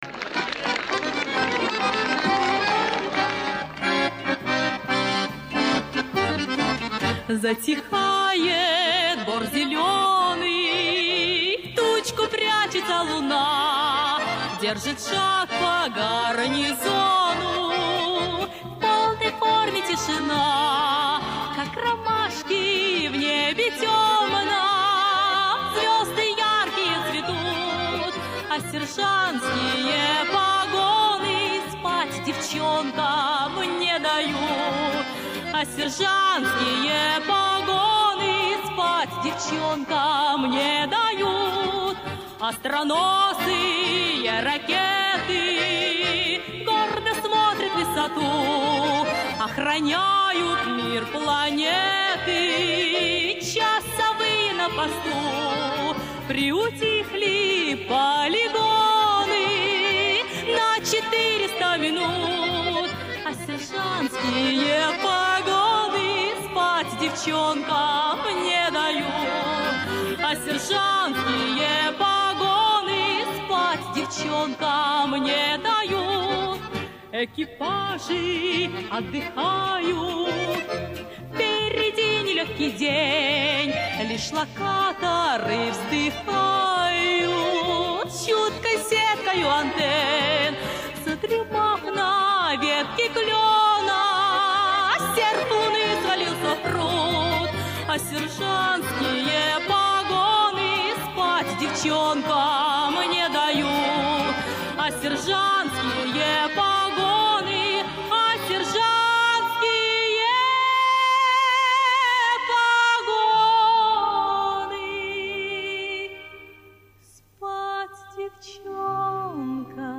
Песня из музыкального фильма